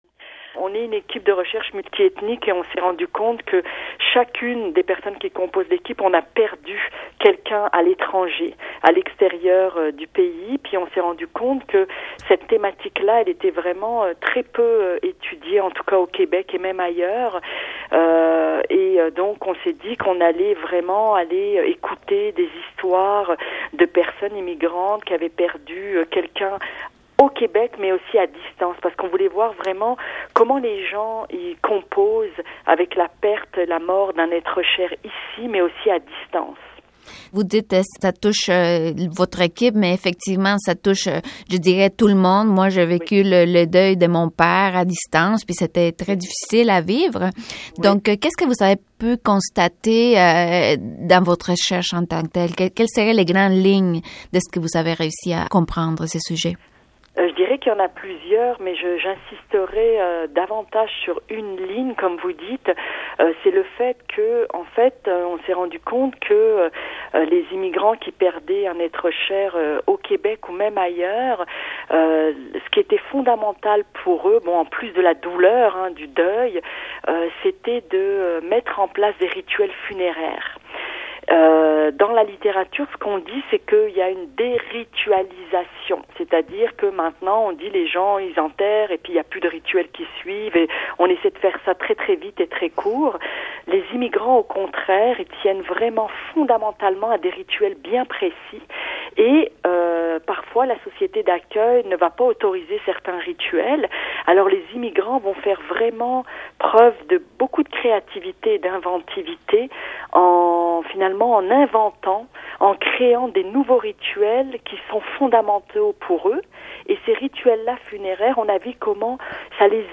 explique en entrevue à Radio Canada International